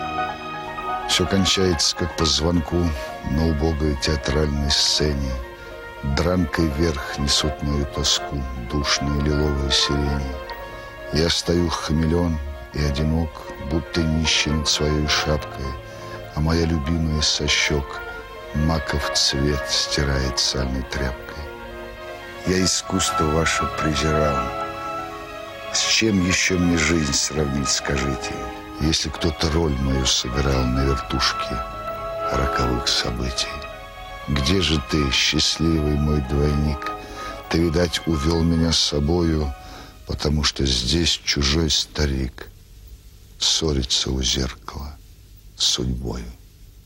1. «(СТ) А.Тарковский читает О.Янковский – “Актер”» /